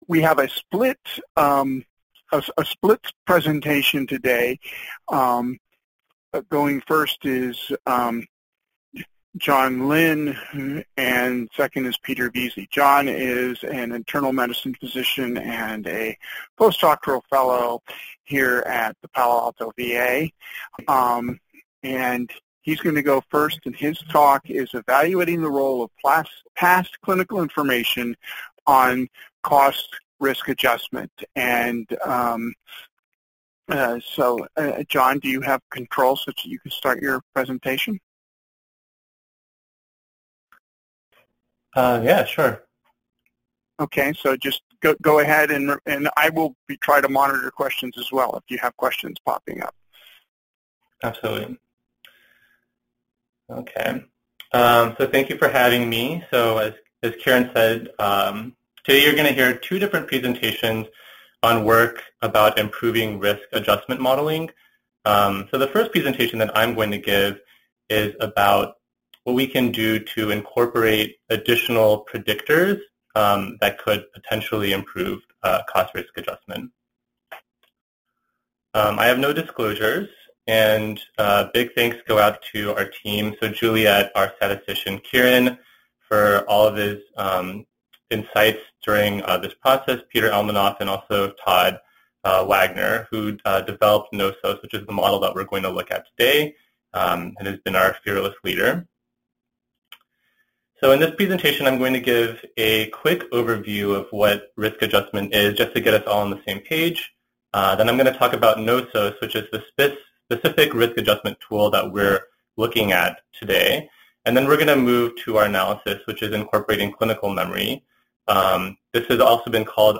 HERC Health Economics Seminar